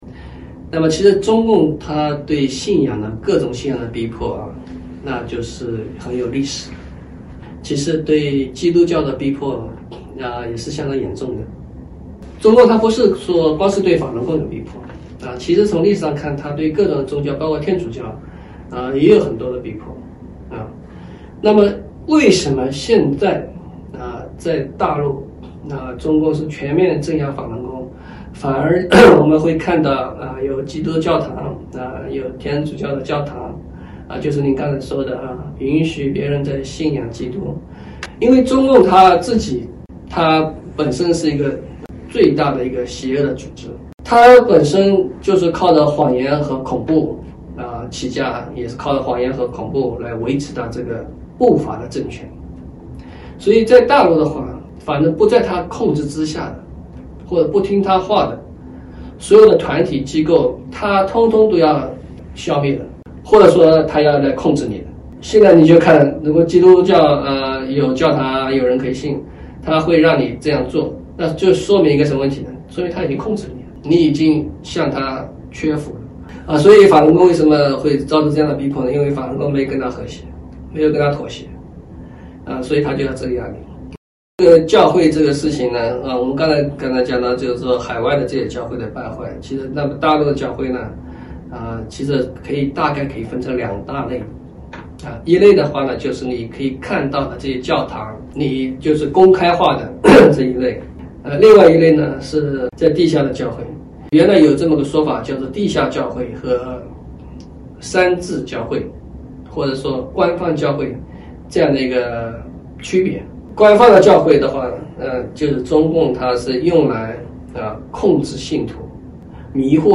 7月11日（星期日）一周新闻回顾